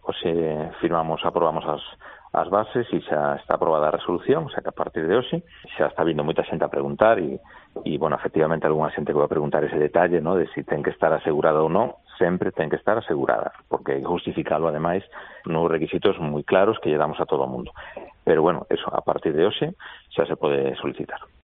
El alcalde de Ponte Caldelas aclara una de las condiciones del bono municipal para conciliación